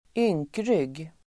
Ladda ner uttalet
Uttal: [²'yng:kryg:]